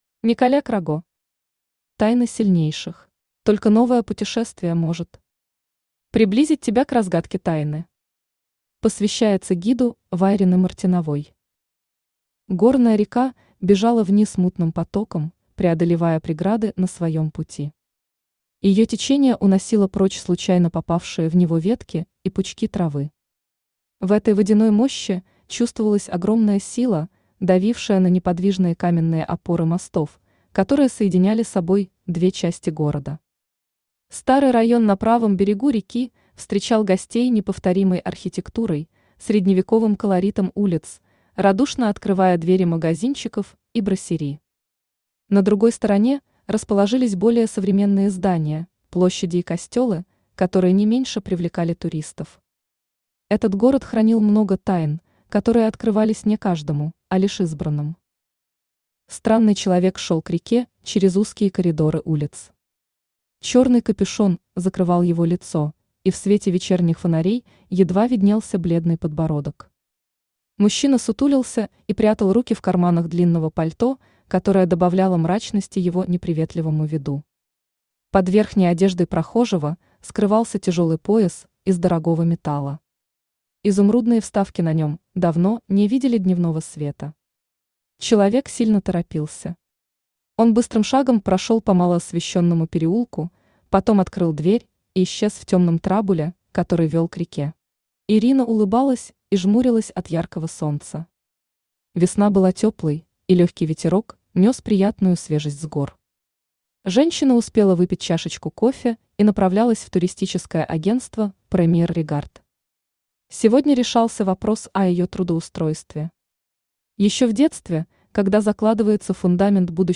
Aудиокнига Тайна сильнейших Автор Николя Краго Читает аудиокнигу Авточтец ЛитРес.